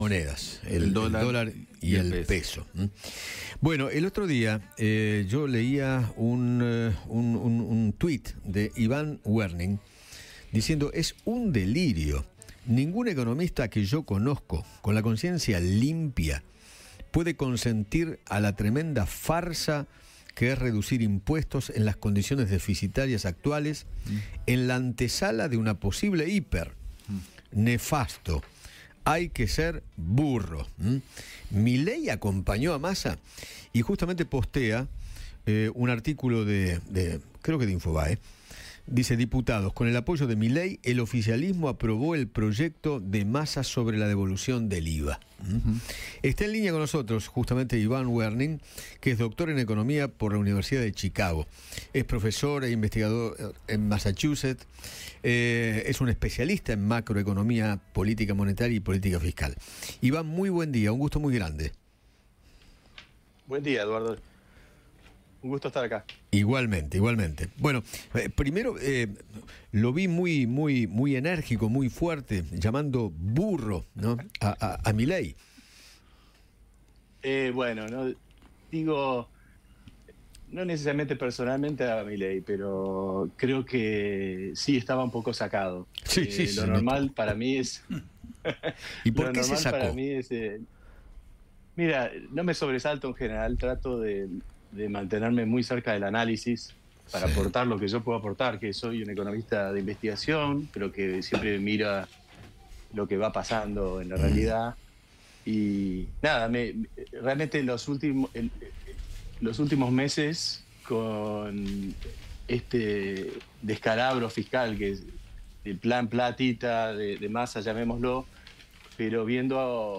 Iván Werning, Profesor en el Departamento de Economía del MIT, dialogó con Eduardo Feinmann sobre el apoyo de Javier Milei a los proyectos de reforma de Ganancias y de devolución del IVA, impulsados por Sergio Massa.